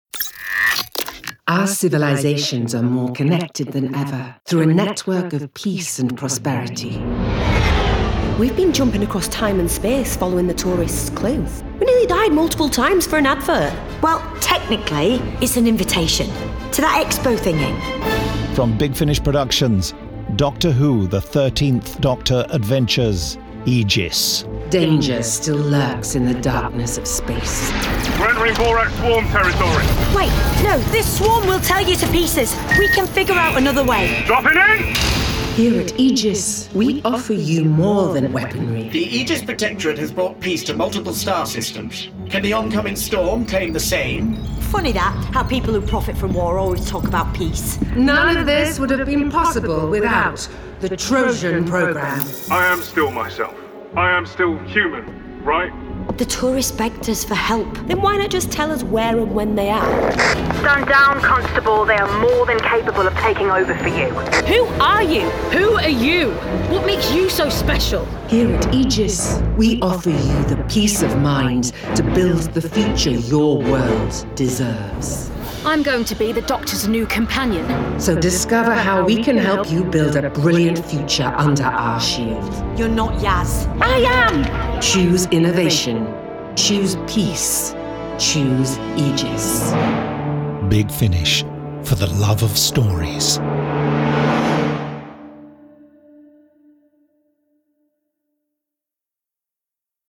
Starring Jodie Whittaker Mandip Gill